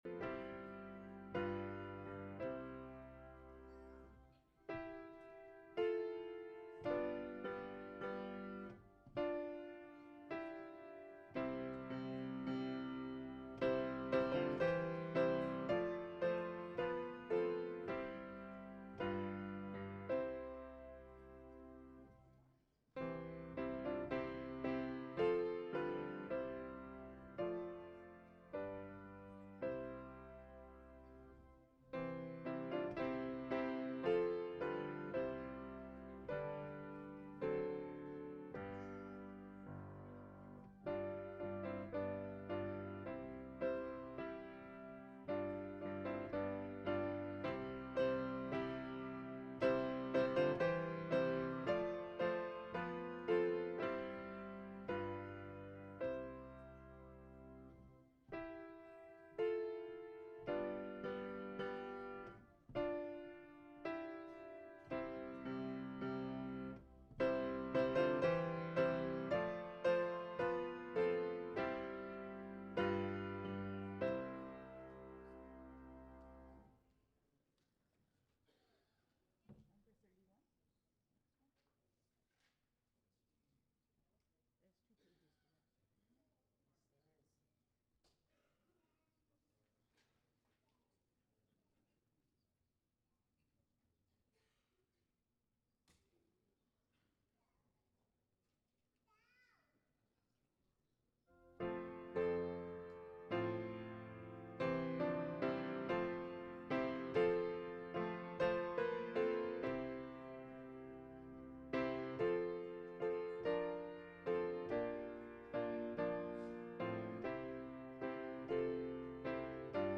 Articles Of Faith/Mother's Day Service